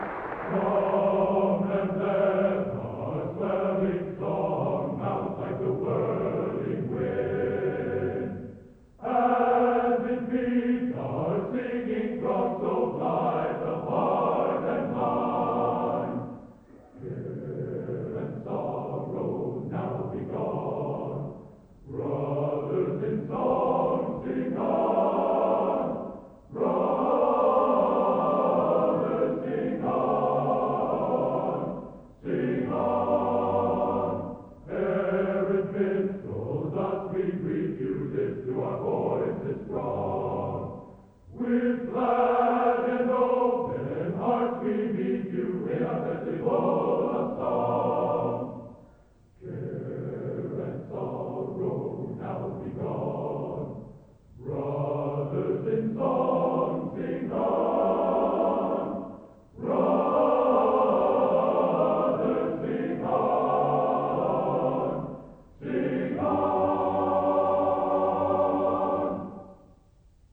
Collection: South Bend 1990